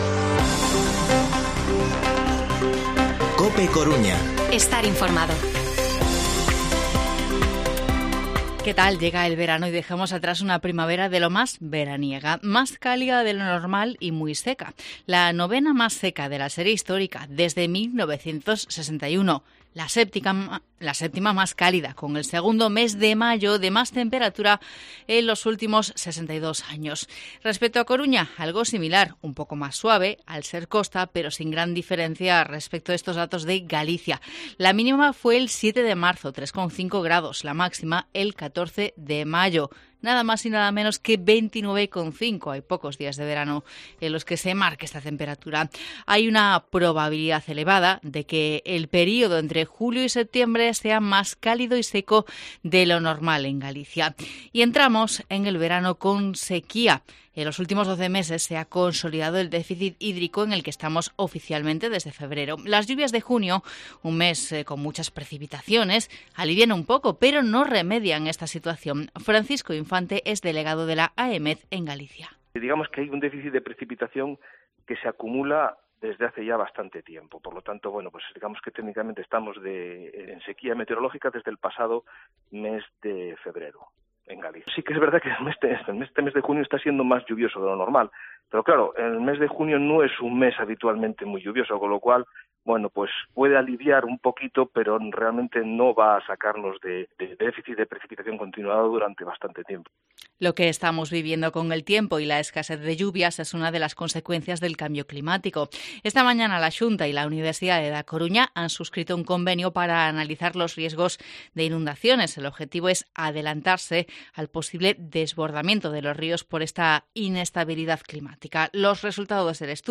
Informativo Mediodía COPE Coruña martes, 21 de junio de 2022 14:20-14:30